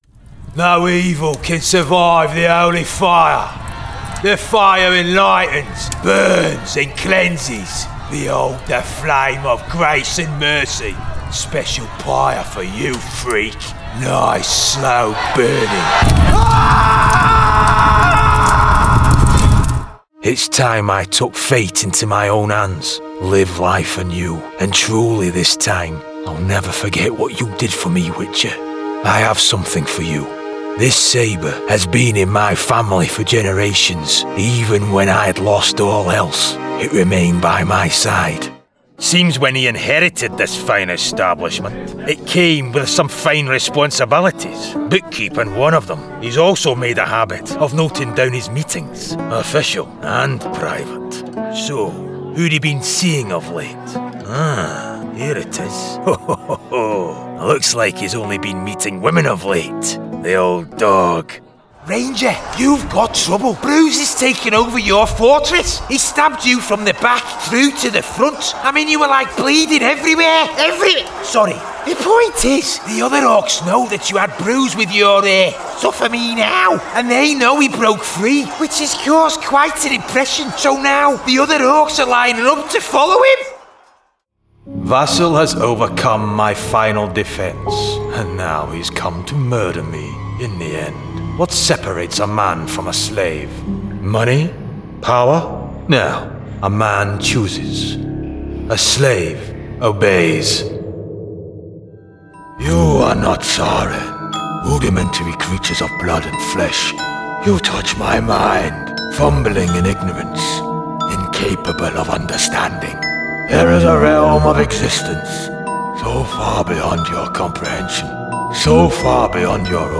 Video Game Reel
• Native Accent: Glasgow, Yorkshire
Powerful and gritty